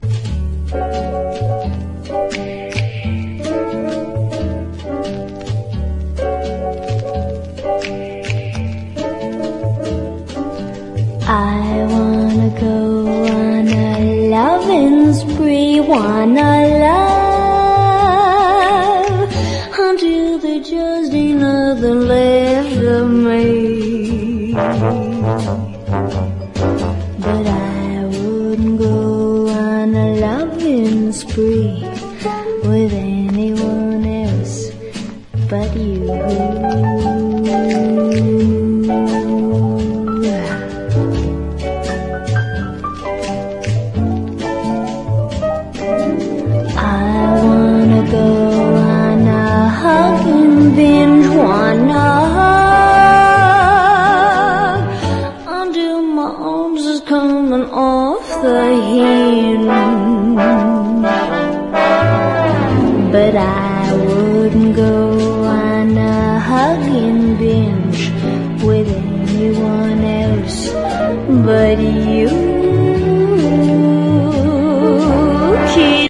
EASY LISTENING / VOCAL / TWIST / OLDIES